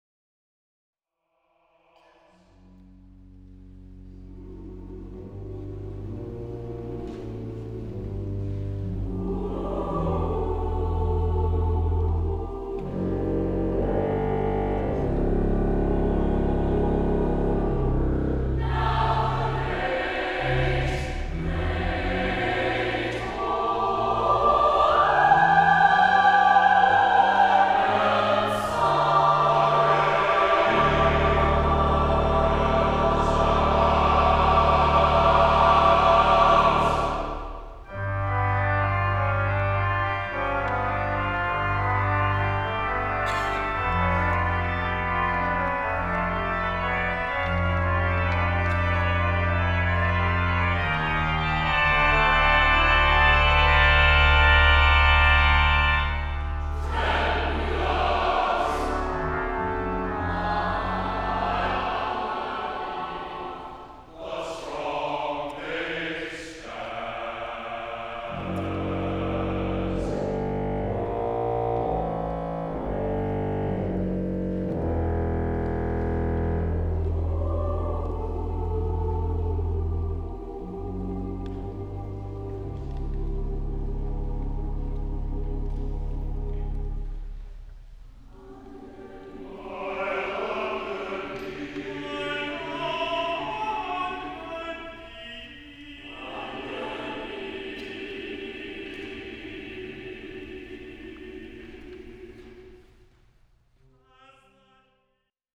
chorus (SATB) & organ